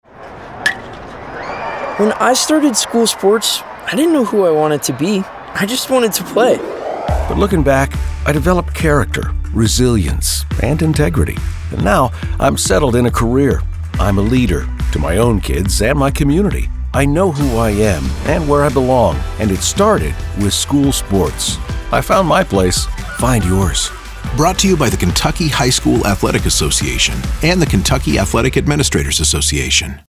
25-26 Radio – Public Service Announcements